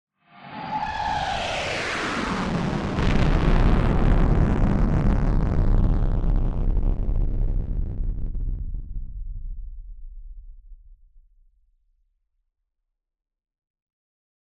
BF_DrumBombDrop-03.wav